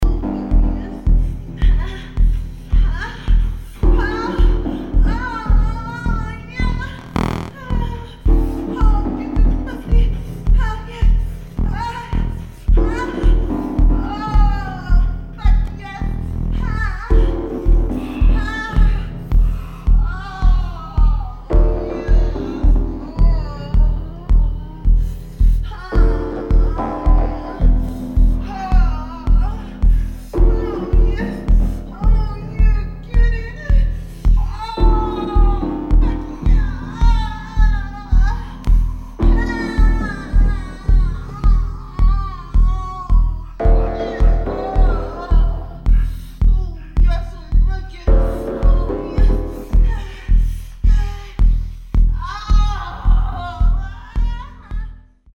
[ TECHNO / MINIMAL / ACID / HOUSE ]